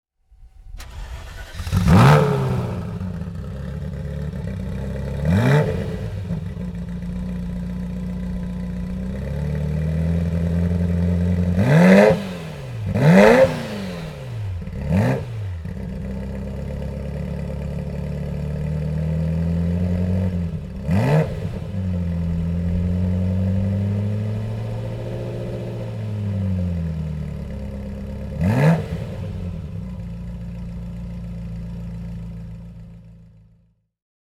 Ferrari F40 (1990) - eight cylinders, 2.9 liter displacement and 478 hpFerrari F40 (1990) - eight cylinders, 2.9 liter displacement and 478 hp
Ferrari F40 (1990) - Starten und Leerlauf